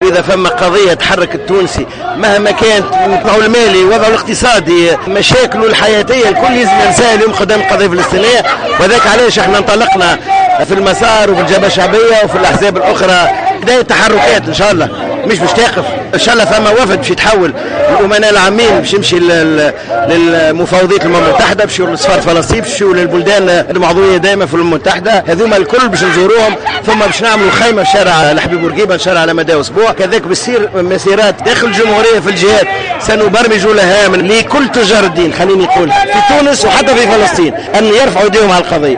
قال القيادي بحزب "المسار" سمير بالطيب في تصريح لـ "الجوهرة أف أم" على هامش مسيرة شعبية لمساندة الفلسطينيين اليوم السبت، إنه ستكون هناك تحركات في تونس في الأيام المقبلة لدعم الشعب الفلسطيني في مواجهته الحالية للاحتلال الإسرائيلي.